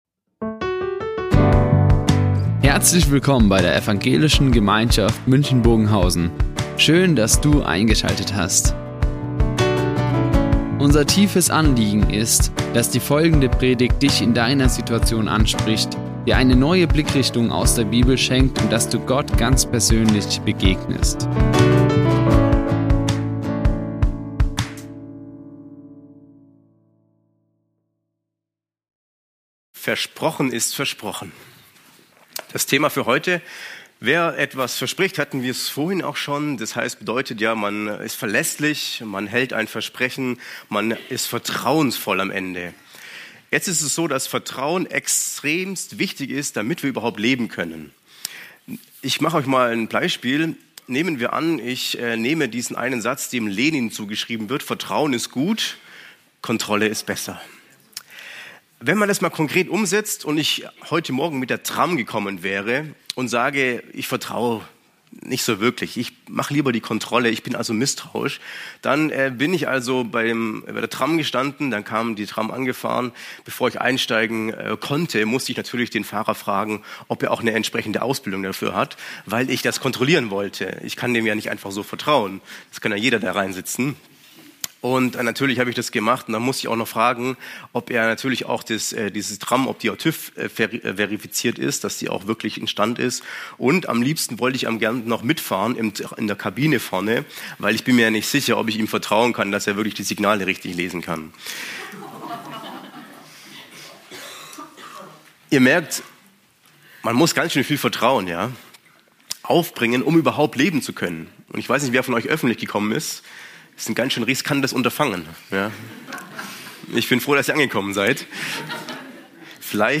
Advent - versprochen ist versprochen | Predigt 2.
Die Aufzeichnung erfolgte im Rahmen eines Livestreams.